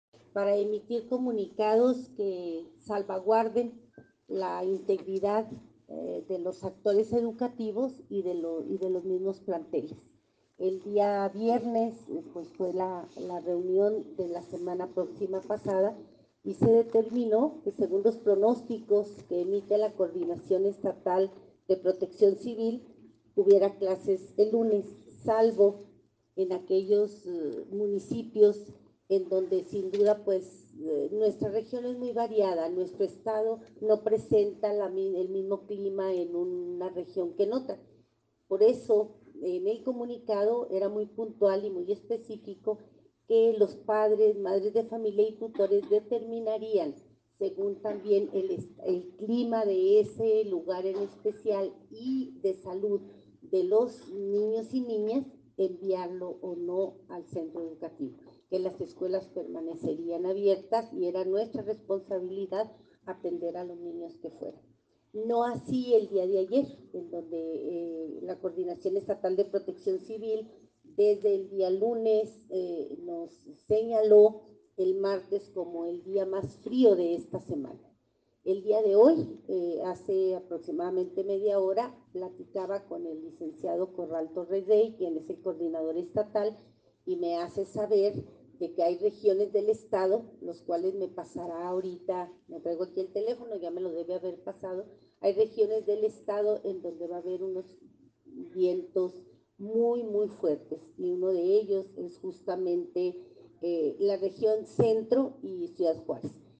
AUDIO: SANDRA GUTÍERREZ, TITULAR DE LA SECRETARÍA DE EDUCACIÓN Y DEPORTE (SEyD)